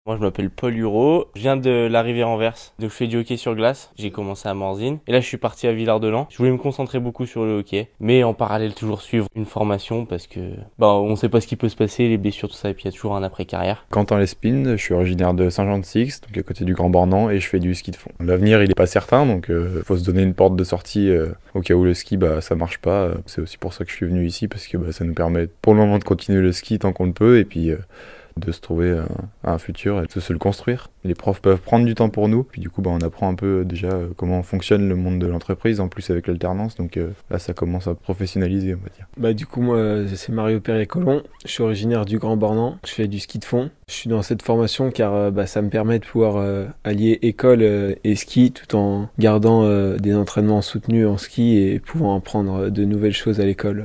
Ces trois jeunes athlètes font partie de la première promo et ils expliquent pourquoi ils ont fait ce choix.